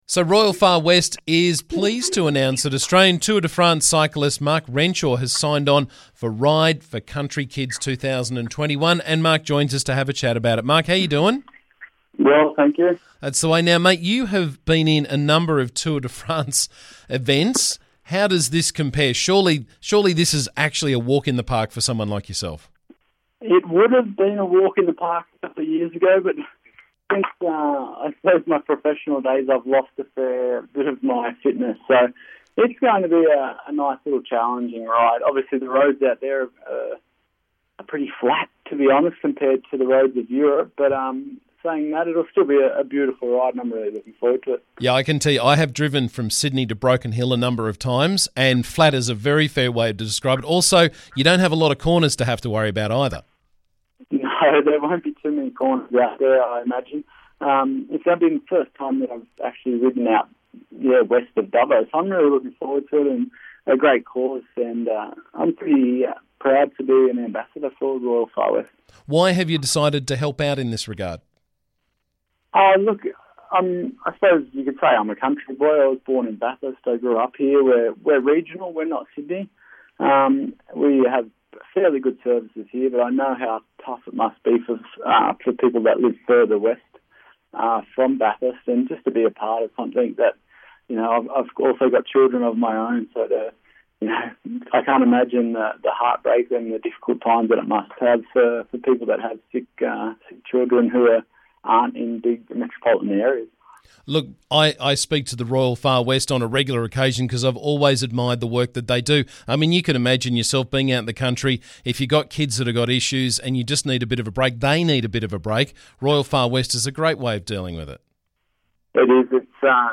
Former Australian Tour de France cyclist Mark Renshaw is taking on the Royal Far West 'Ride for Country Kids' fundraiser and he joined me to tell us why this morning.